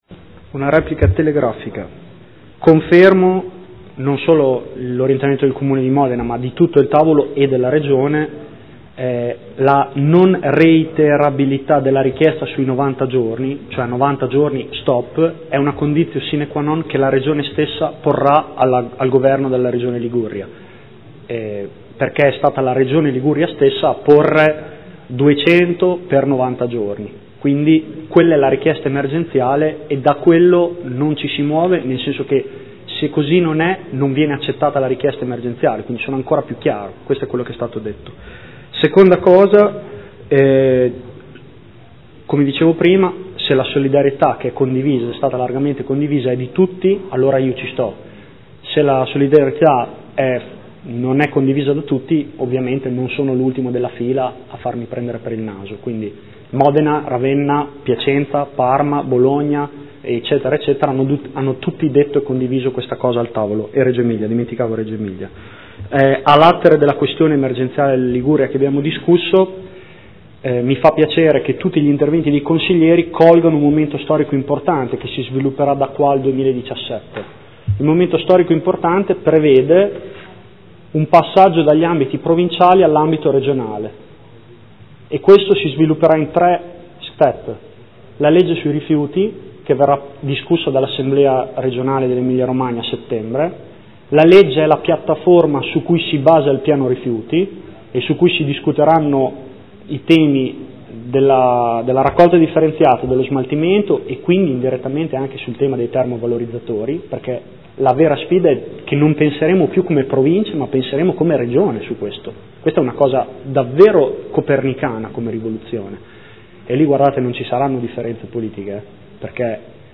Seduta del 23/07/2015 Conclusione. Interrogazione. Rifiuti provenienti dalla Liguria a Modena?